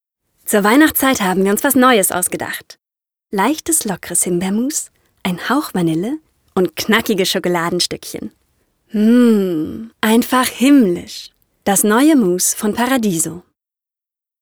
Authentische, von jung frisch frech über verträumt sinnlich bis dynamisch seriöse Stimme.
Sprechprobe: Sonstiges (Muttersprache):
Female voice over artist German/English. Authentic voice, from young and fresh to sleepy sensual and dynamic respectable.